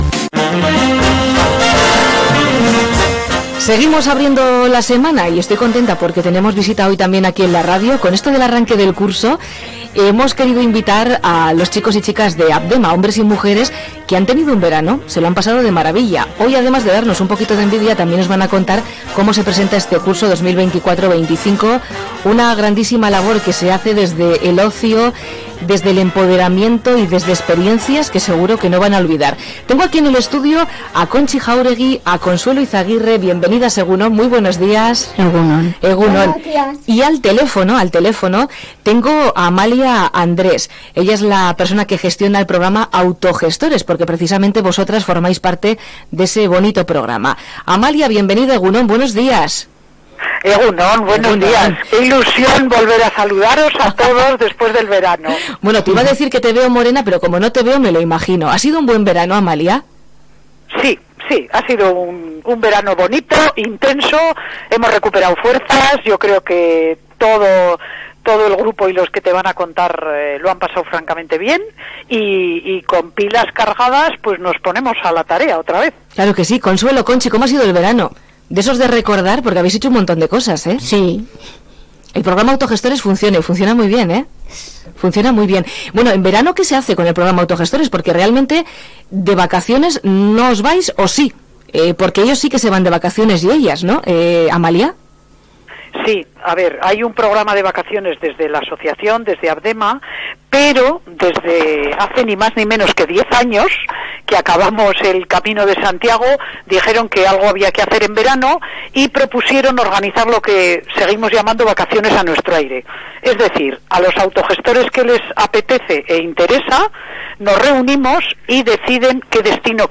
El pasado lunes, 23 de septiembre, tres personas usuarias de los grupos de Autogestores de APDEMA del Valle de Ayala protagonizaron buena parte del programa Alto Nervión Gaur de Radio Llodio.
entrevista